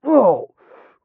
m_pain_7.ogg